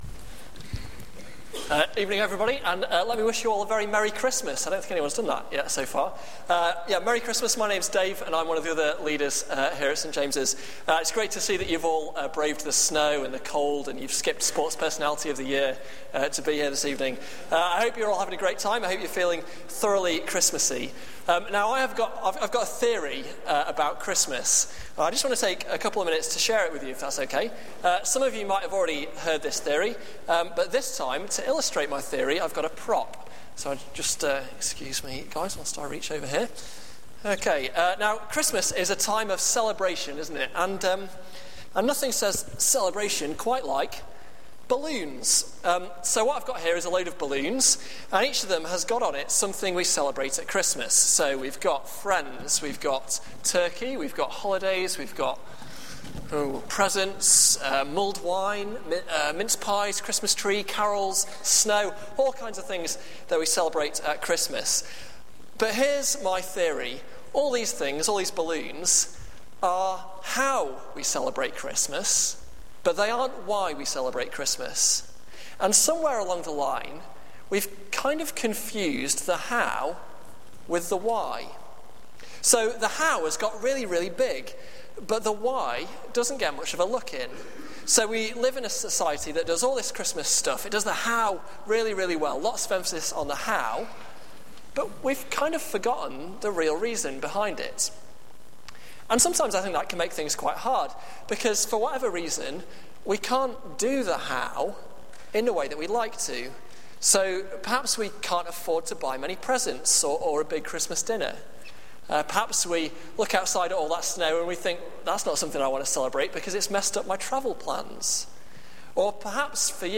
Carol Service 2010